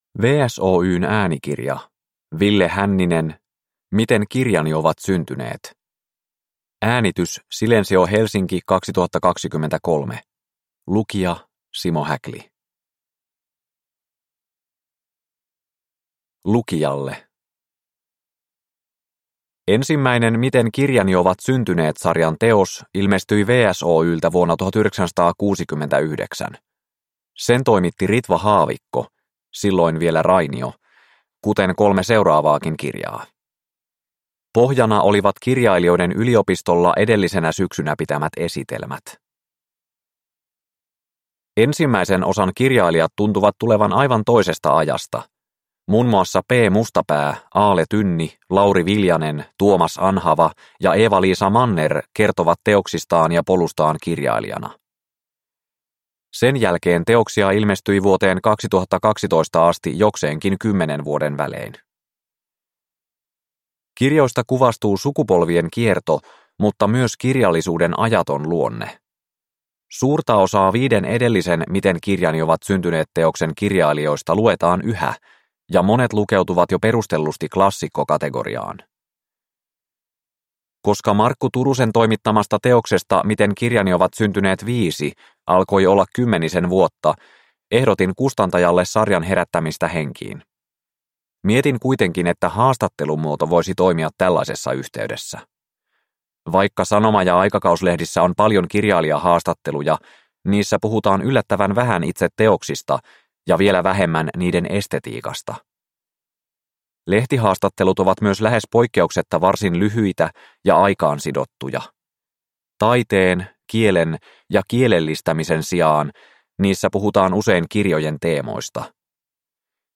Miten kirjani ovat syntyneet 6 – Ljudbok